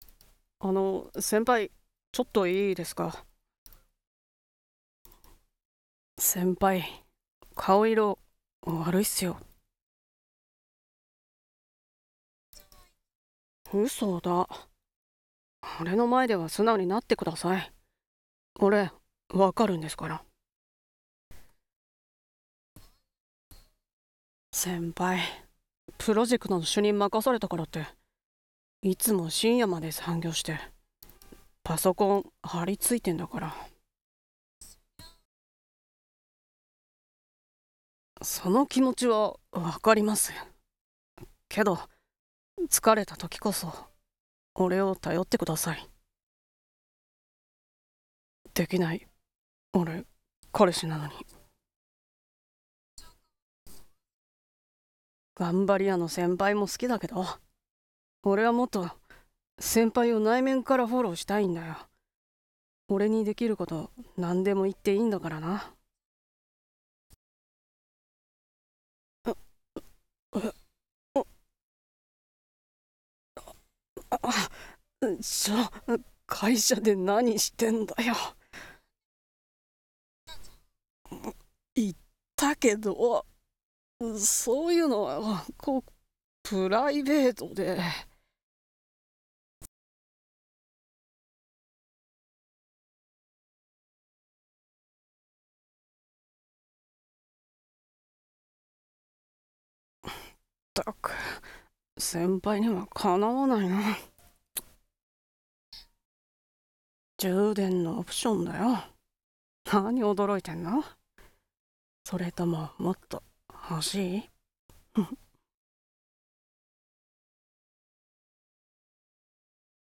君には敵わないな…ww【声劇台本】